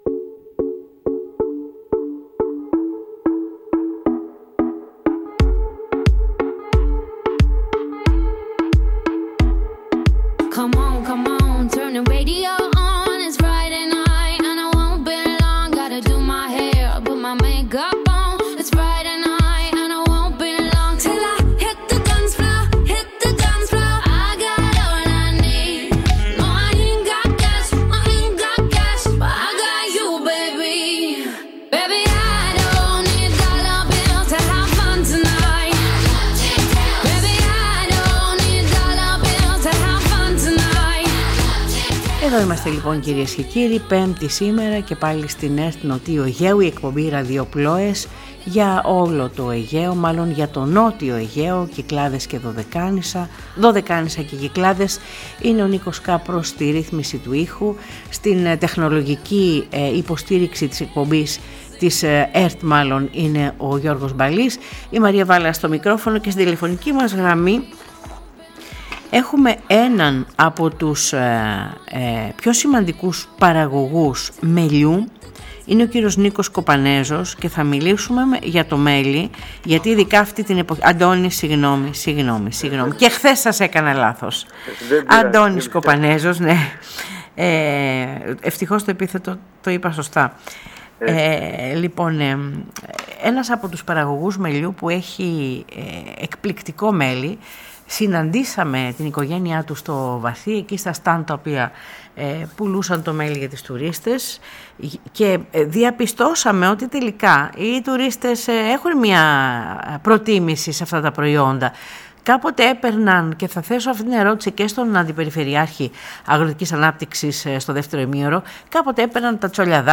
Στο δεύτερο μέρος της εκπομπής ακούμε τη συνέντευξη του Δημάρχου Λειψών κ. Φώτη Μάγγου, για τον εορτασμό της Παναγιάς του Χάρου στο νησί το ερχόμενο Σαββατοκύριακο, με την παρουσία της Ιεράς Πατριαρχικής Εξαρχίας Πάτμου, πολιτικών, στρατιωτικών αρχών και πλήθους κόσμου από όλα τα νησιά.